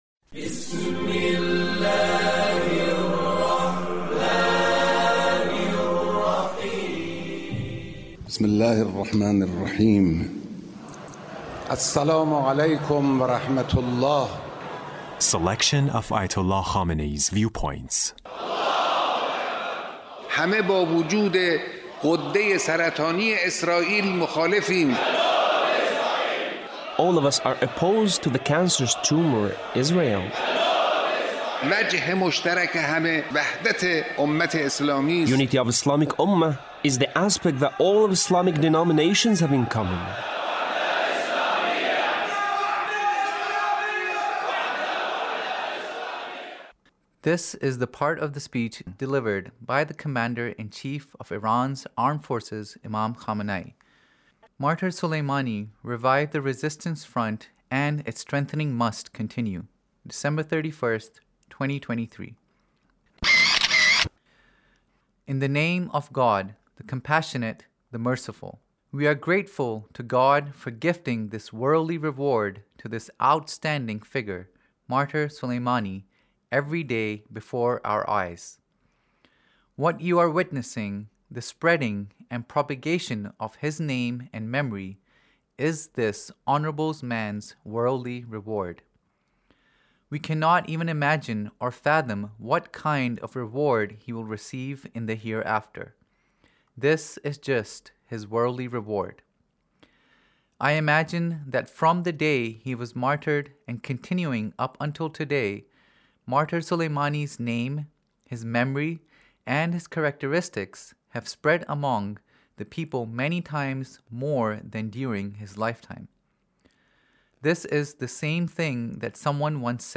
Leader's Speech in a meeting with Martyr Soleimani's Family